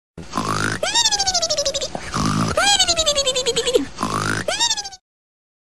*Snore* mimimimimimi
snore-mimimimimimi-soundbuttonsboard.net_.mp3